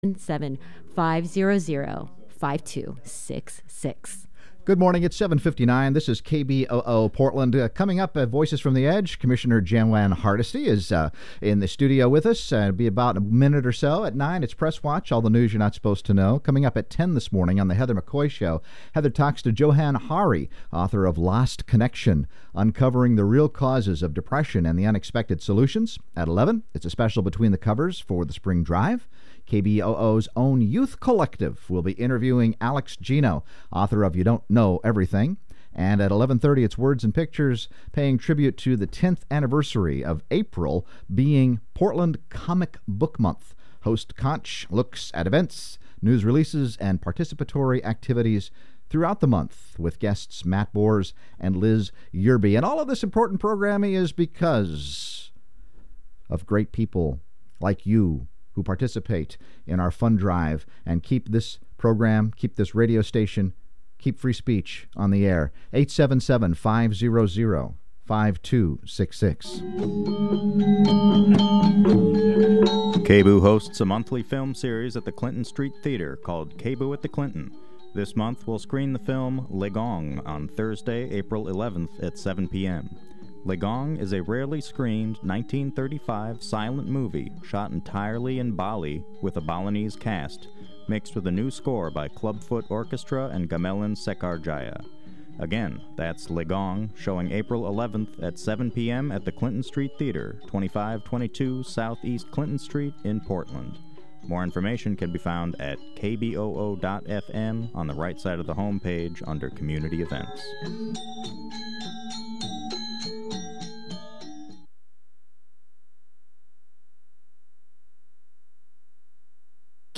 Progressive talk radio from a grassroots perspective